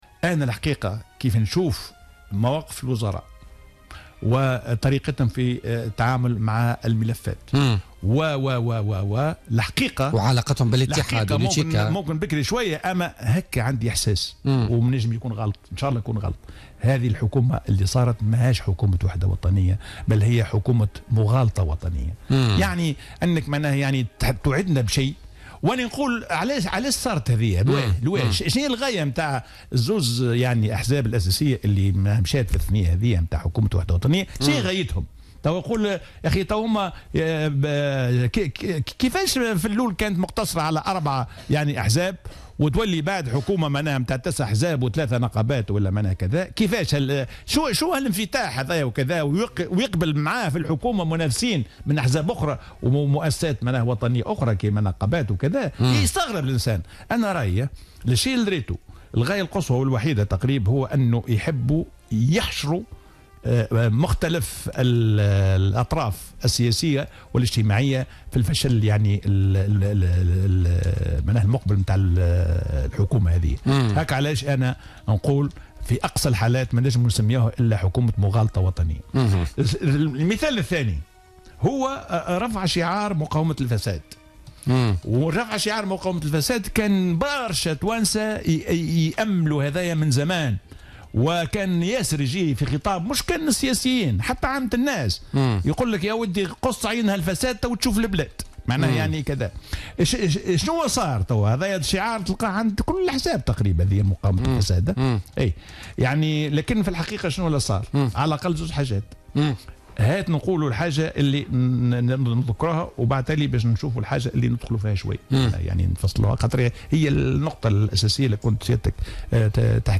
اعتبر الخبير الاقتصادي ووزير المالية الأسبق حسين الديماسي في تصريح للجوهرة أف أم اليوم الجمعة 18 نوفمبر 2016 في برنامج بوليتكا أن حكومة الوحدة الوطنية هي حكومة "مغالطة وطنية" ولا فائدة ترجى منها وفق تعبيره.